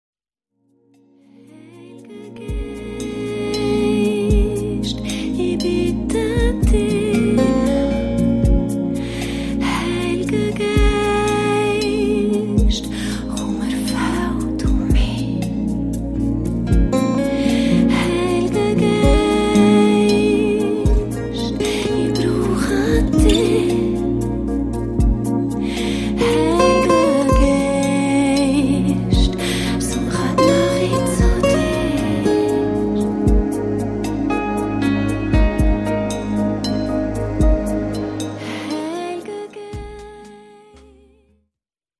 Songs (Lead Vocals)